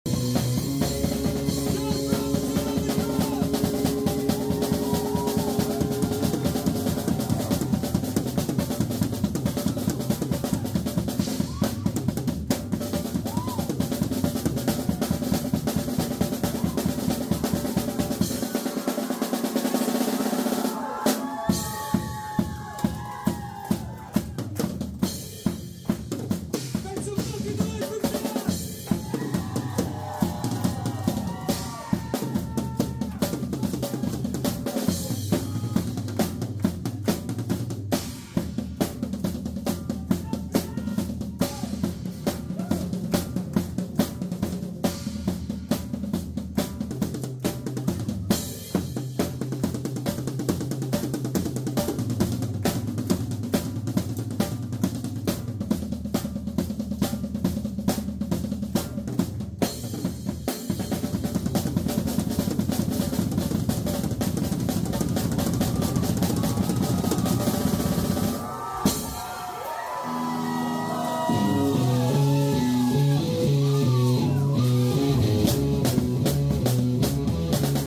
drum solo